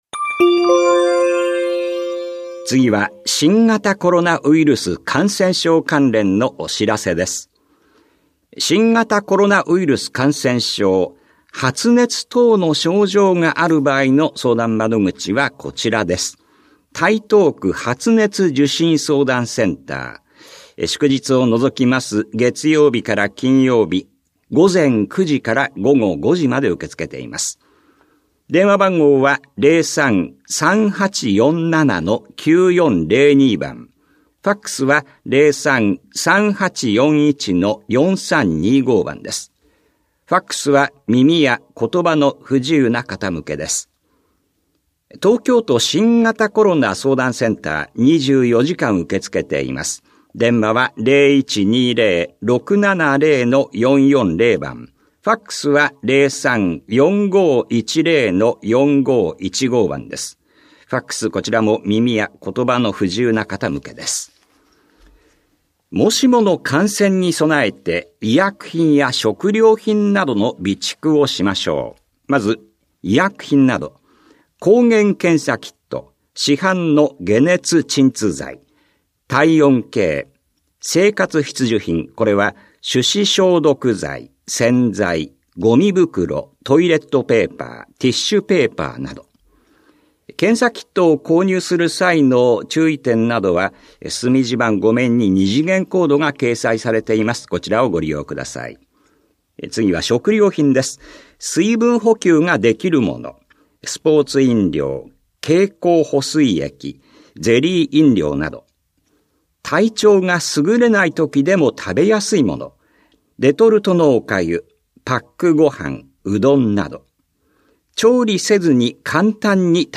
広報「たいとう」令和5年7月20日号の音声読み上げデータです。